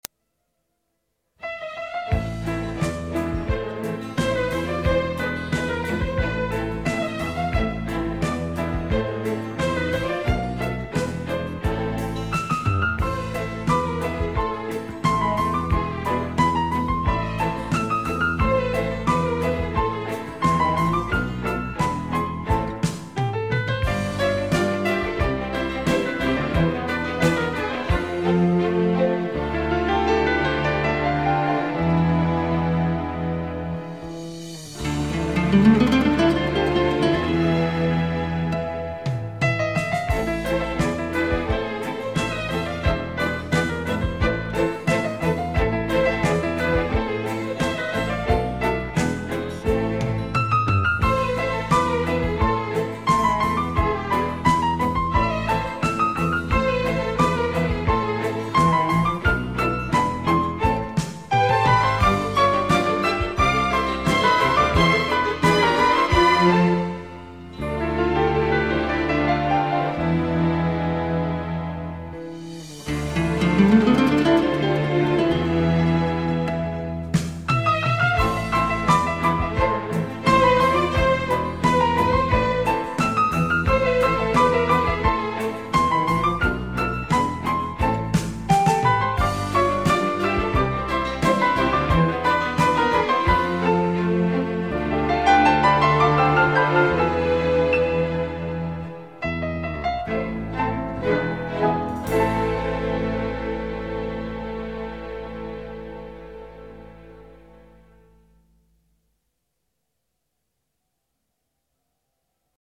0510-钢琴名曲幸福岁月.mp3